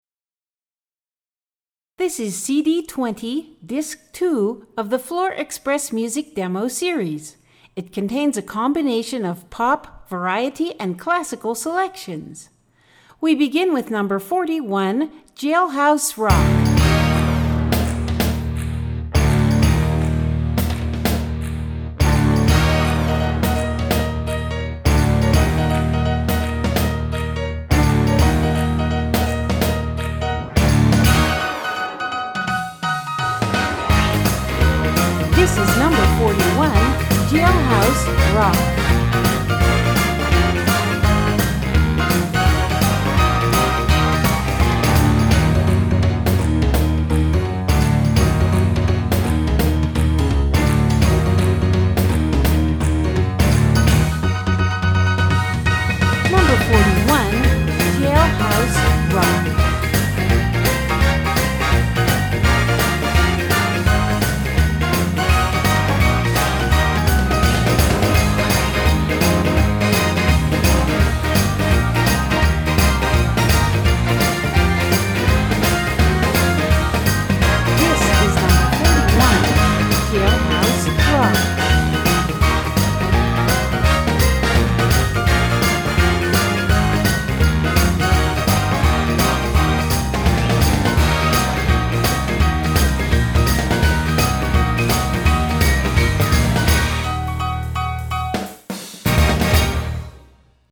1:23 Style: Rock Category